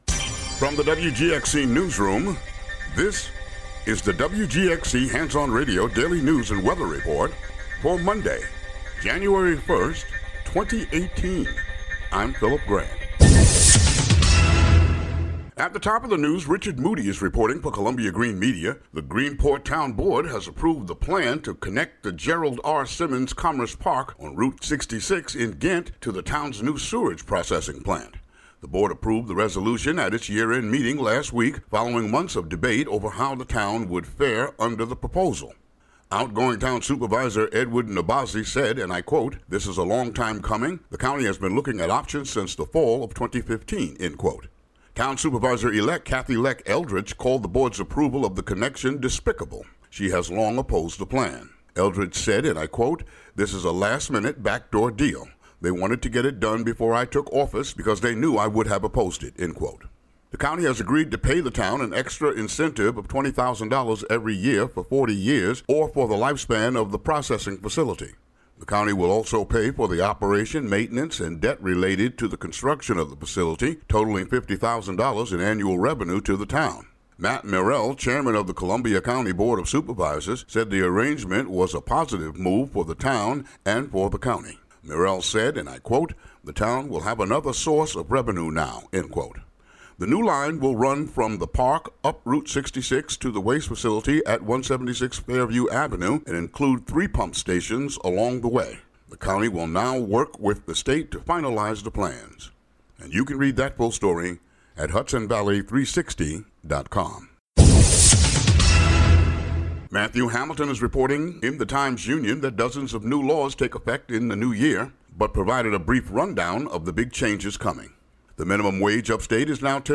Daily local news for Mon., Jan. 1.